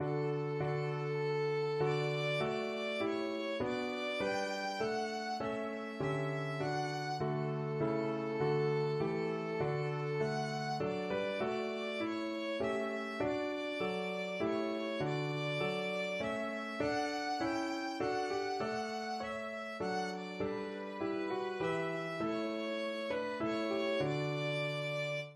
Christian
Arrangement for Violin and Piano
3/4 (View more 3/4 Music)
Classical (View more Classical Violin Music)